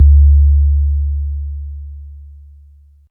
Big 808.wav